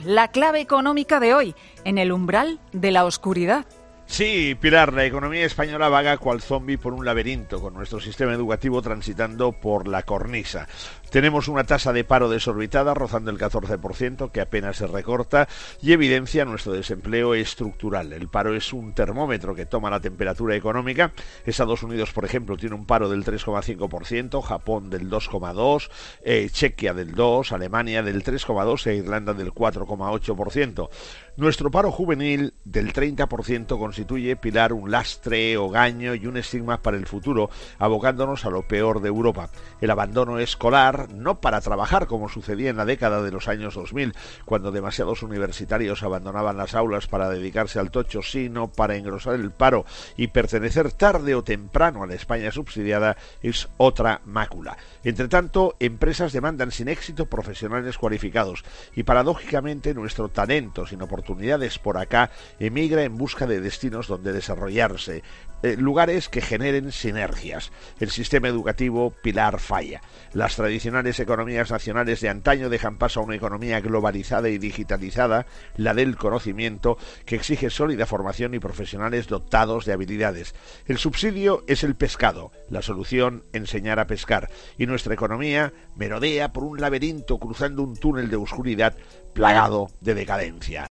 El profesor nos habla este lunes en "Herrera en COPE" sobre el sistema educativo en España
Gay de Liébana analiza cada mañana en 'Herrera en COPE' la actualidad económica del día.